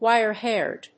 アクセントwíre‐háired
音節wíre-hàired